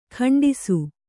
♪ khaṇḍisu